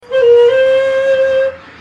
SONS ET LOOPS DE SHAKUHACHIS GRATUITS
Shakuhachi 48